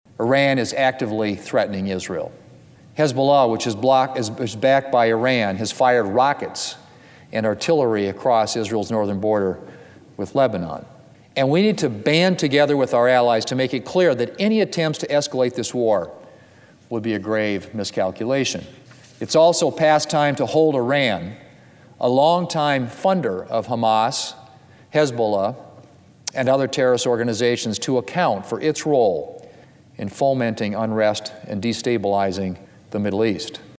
During a recent speech (Oct. 17, 2023) on the floor of the United States Senate, South Dakota Senator John Thune condemned the attacks on Israel by Hamas terrorists.